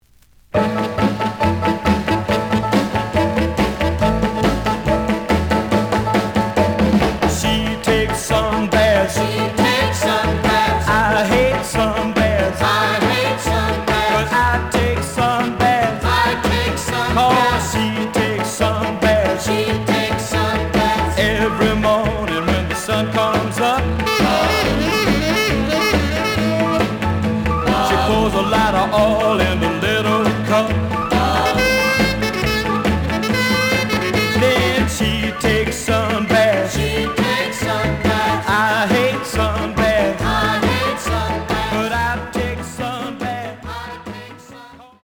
試聴は実際のレコードから録音しています。
●Genre: Rhythm And Blues / Rock 'n' Roll
●Record Grading: VG+ (盤に若干の歪み。多少の傷はあるが、おおむね良好。)